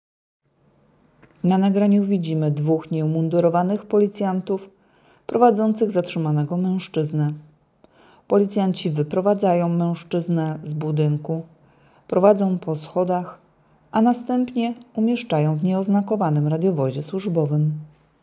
Nagranie audio Deskrypcja filmu